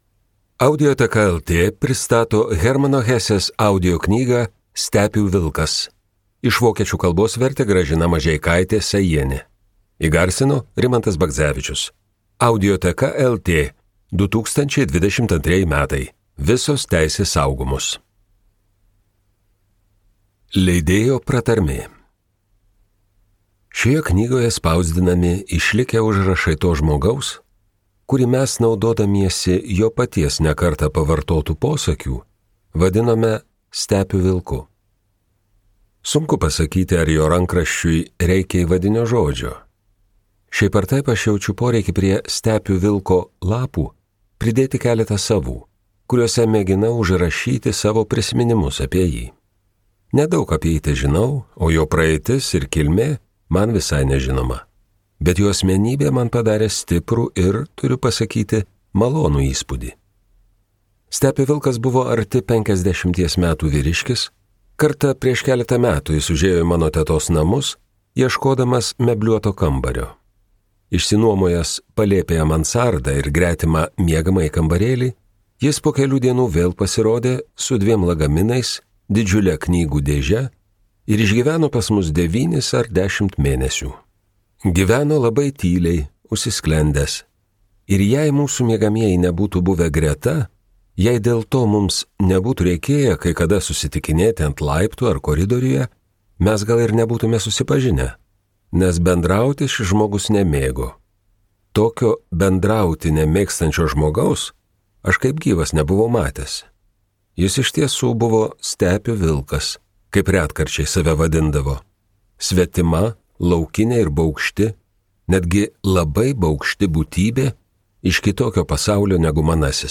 Hermann Hesse audioknyga „Stepių vilkas“ yra literatūros klasika.